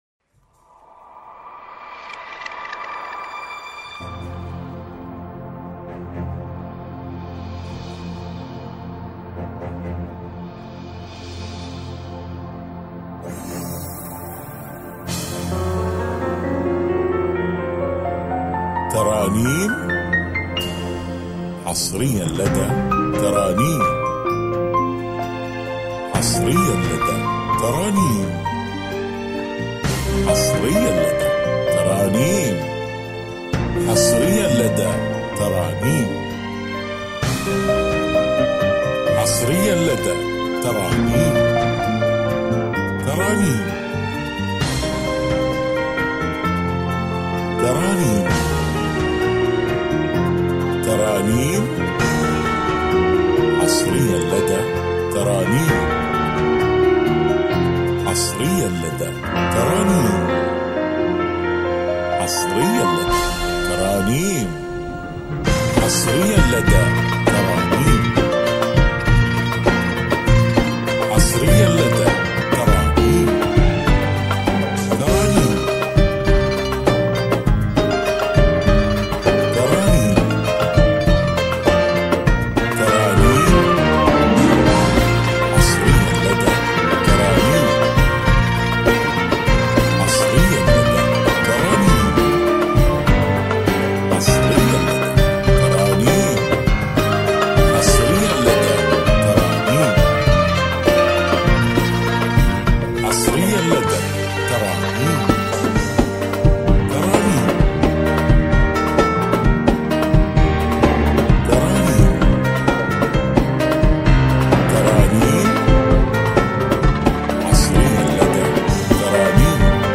موسيقى ملكية
موسيقى هادئة
موسيقى الكمان